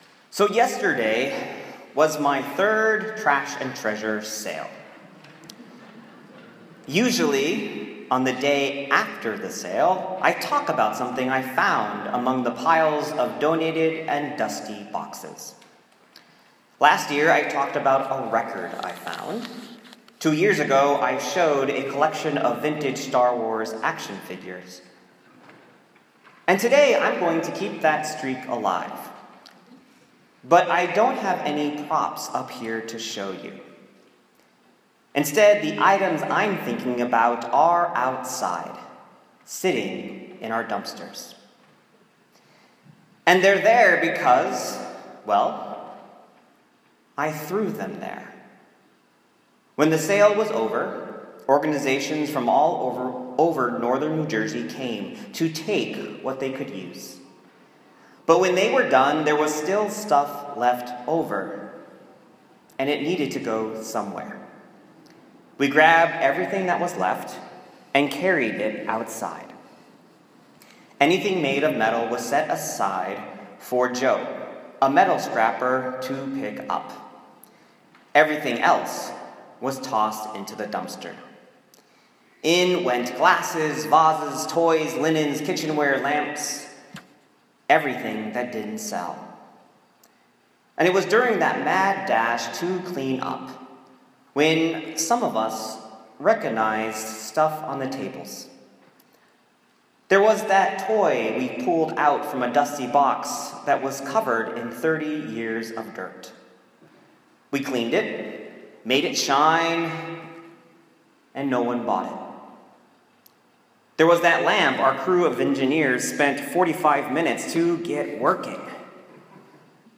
My sermon from 4th Sunday of Easter (May 7, 2017) on Acts 2:42-47.